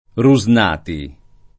[ ru @ n # ti ]